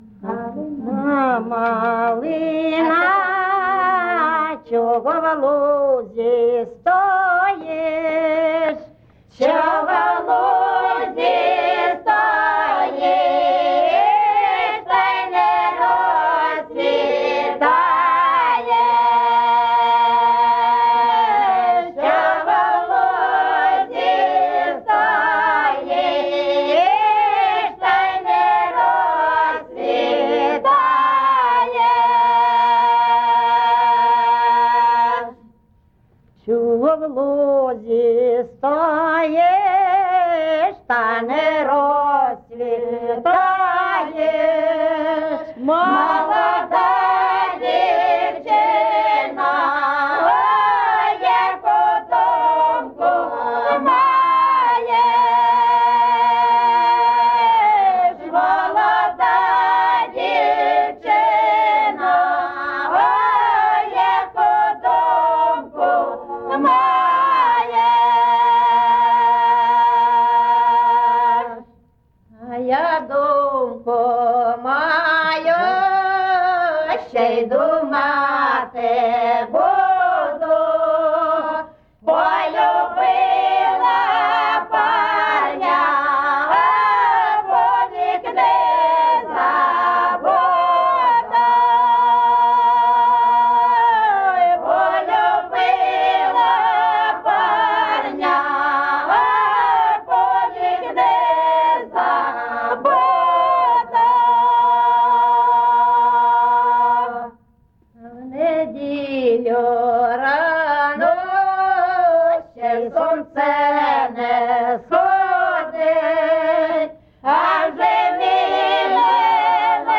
ЖанрКозацькі, Солдатські
Місце записус. Писарівка, Золочівський район, Харківська обл., Україна, Слобожанщина